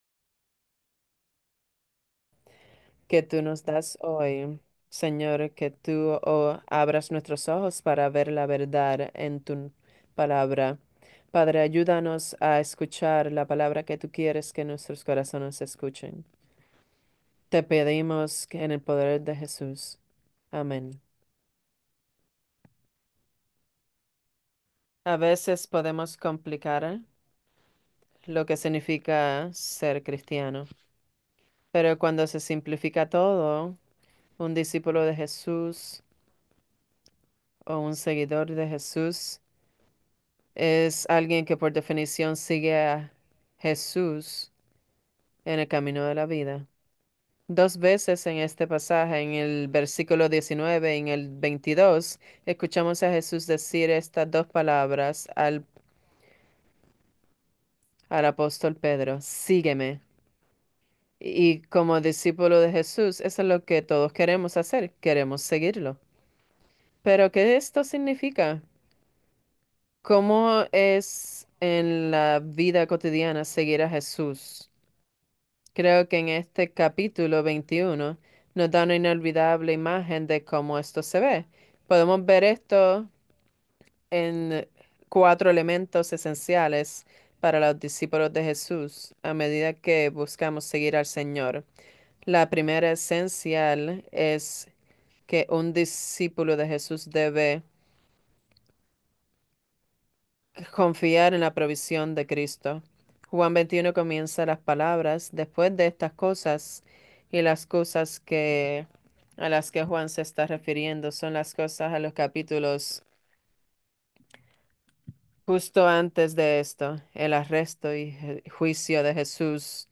In this final message of the Greater Love series